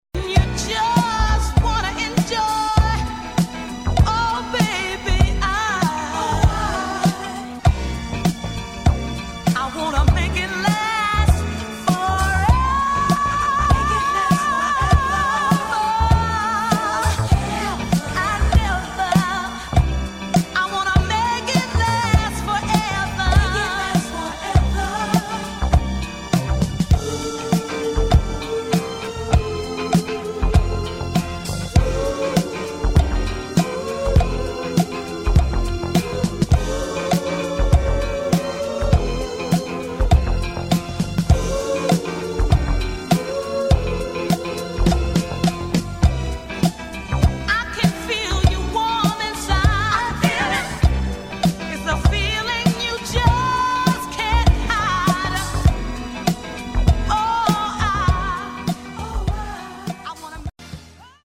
[ DISCO | FUNK | SOUL ]